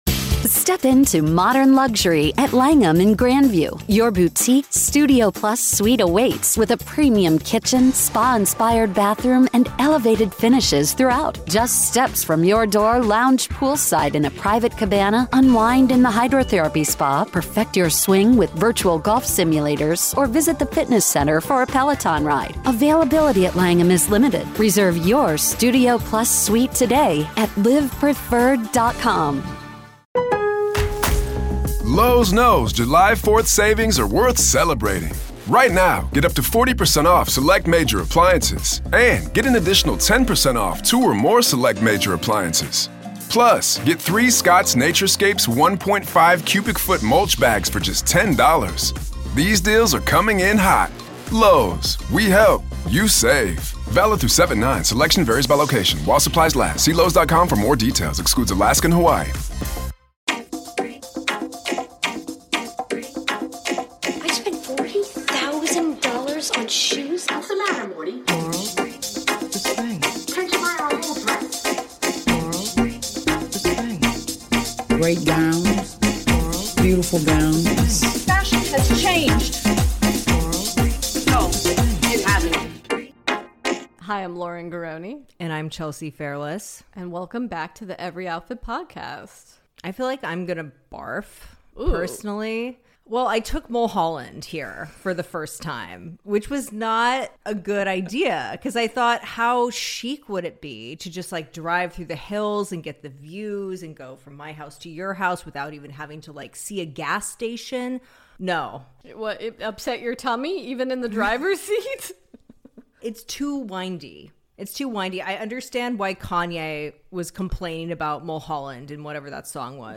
The ladies sit-down to discuss L.A. during Coachella, the Revolve Fest "drama," our conflicted feelings about Harry Styles, the lack of Weird Science in Danny Elfman's set, the Amber Heard / Johnny Depp trial, our MET Gala predictions, Glossier's pivot with Olivia Rodrigo, ScarJo's boring skincare line, Gwen Stefani's overdue make-up collection, katching up on the Blac Chyna/Kardashian lawsuit, reviewing the second and third Kardashians episode, and SO MUCH MORE!